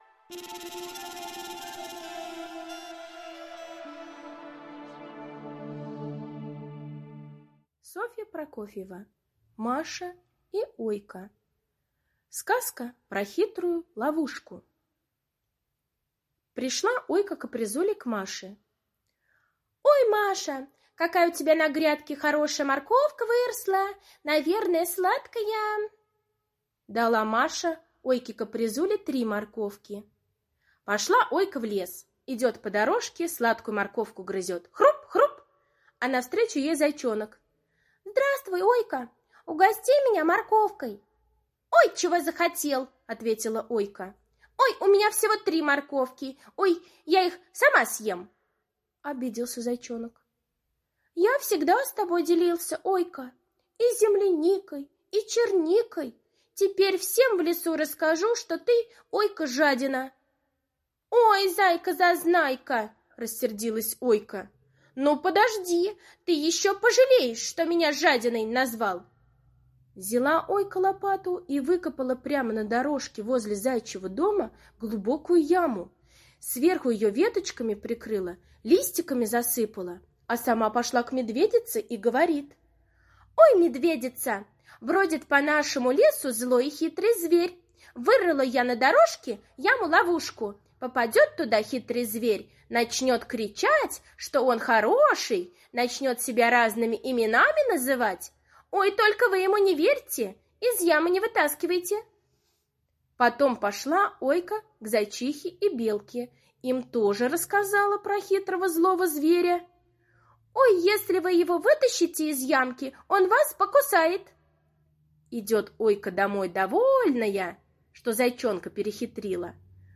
Сказка про хитрую ловушку - аудиосказка Прокофьевой С. Сказка о том, как Ойка сделала яму-ловушку для Зайчонка, да сама в нее и попалась.